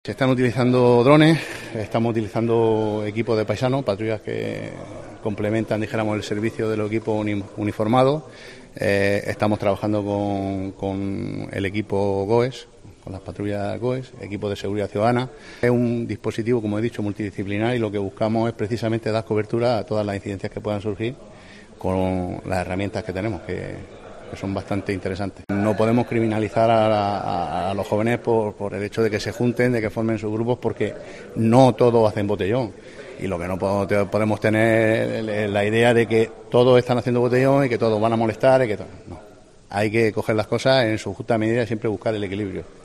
Concejal de Seguridad de Cartagena, José Ramón Llorca, sobre despliegue antibotellón